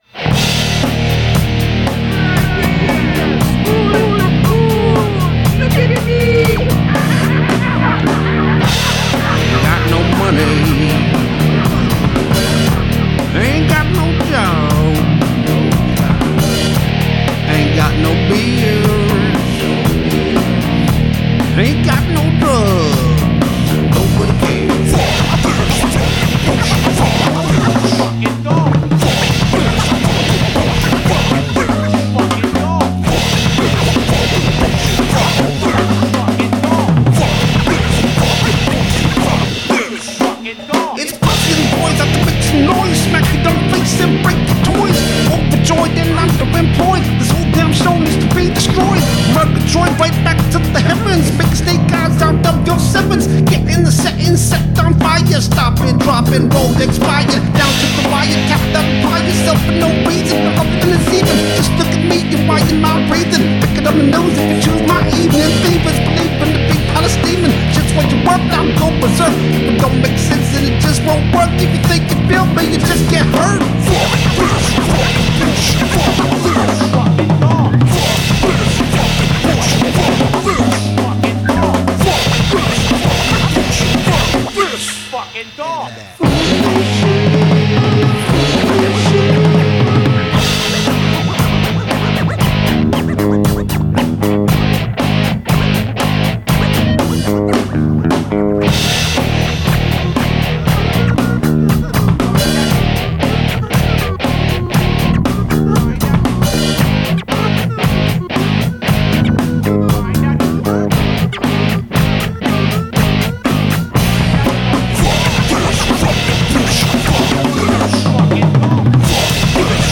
Don’t expect polish.
It’s loud.